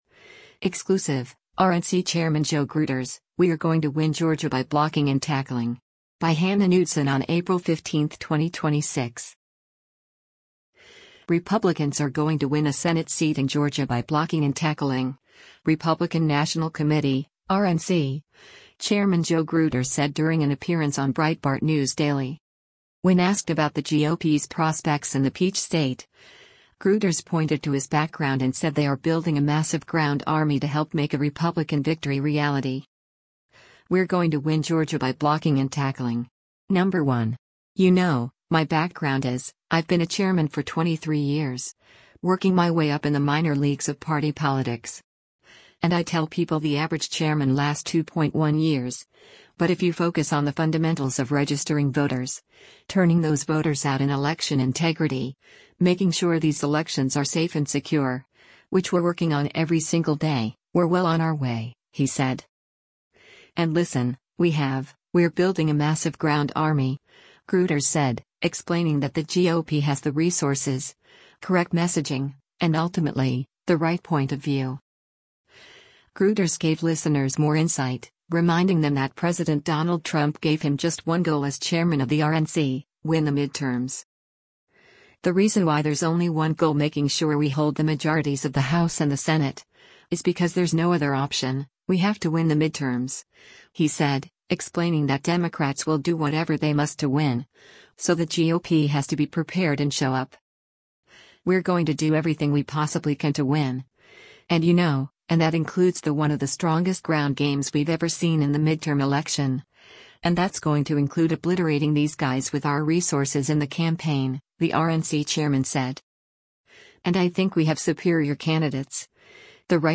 Republicans are going to win a Senate seat in Georgia by “blocking and tackling,” Republican National Committee (RNC) Chairman Joe Gruters said during an appearance on Breitbart News Daily.